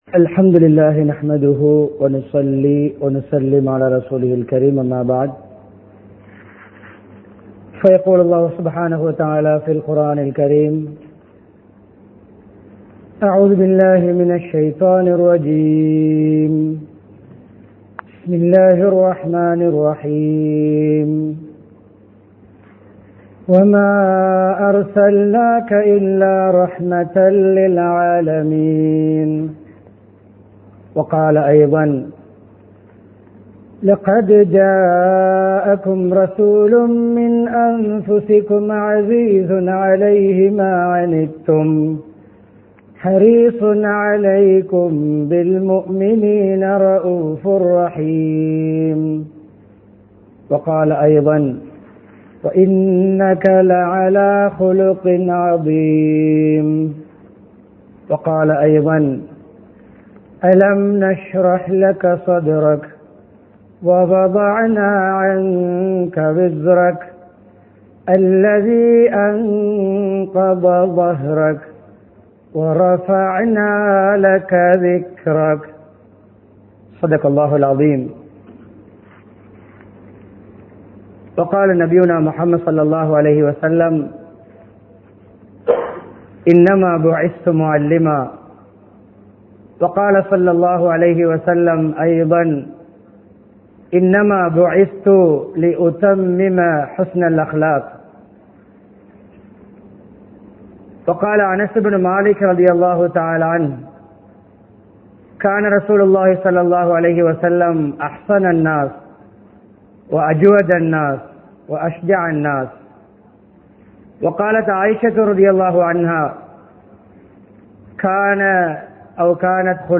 நபி(ஸல்)அவர்களின் அழகு | Audio Bayans | All Ceylon Muslim Youth Community | Addalaichenai
Mutwal Jumua Masjidh